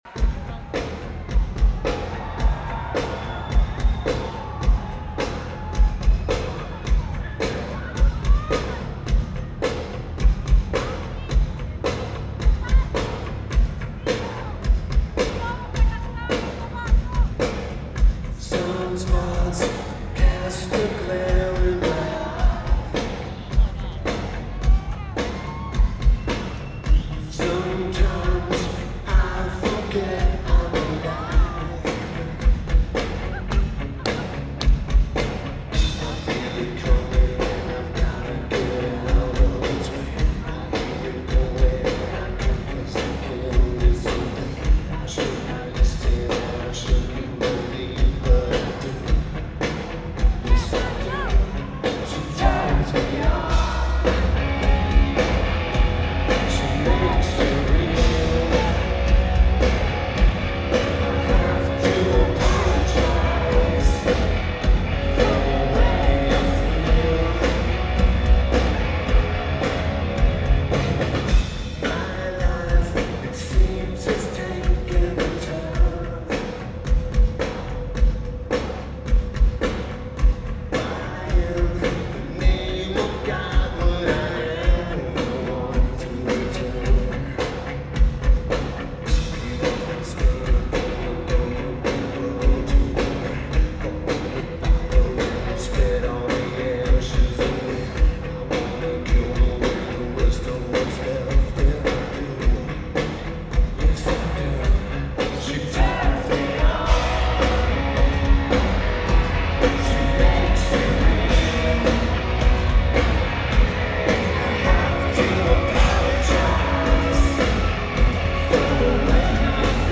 O2 Academy Brixton
Drums
Guitar
Vocals/Guitar/Keyboards